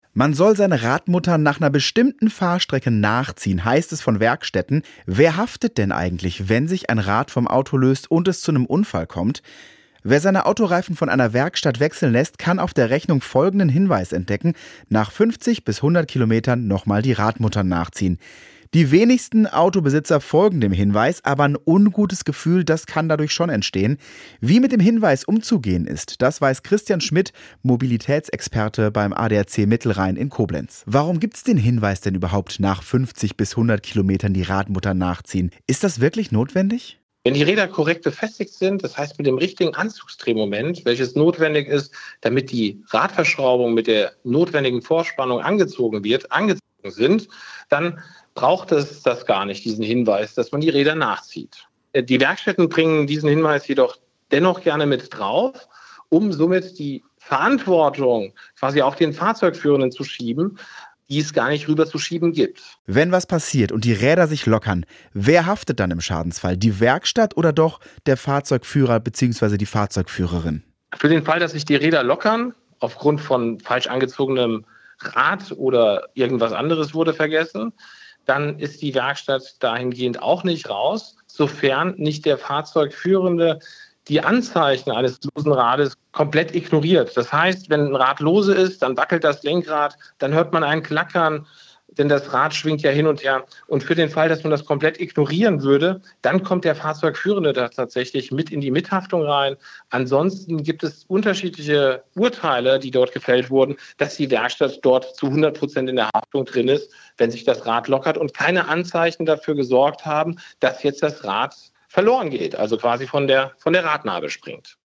Interview mit
Moderator/in